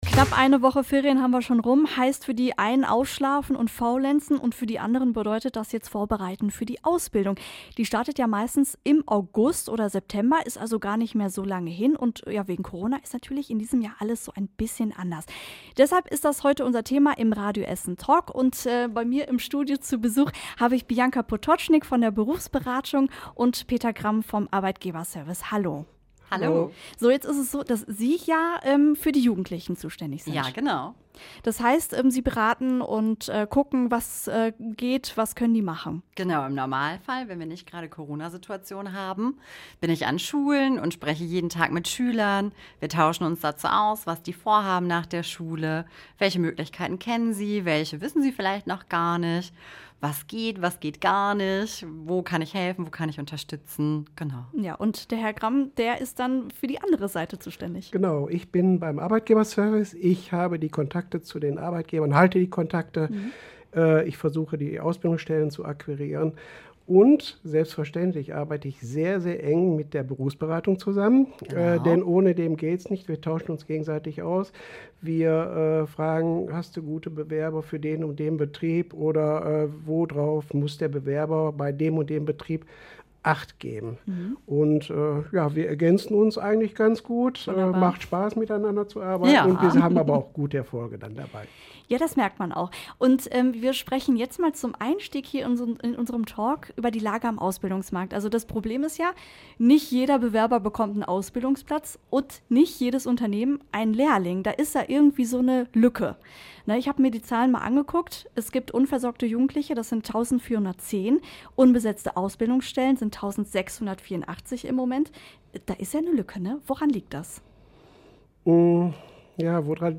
Unsere Fragen im Talk zum Thema Ausbildung 2020: Welche Unterstützung gibt es für Unternehmen?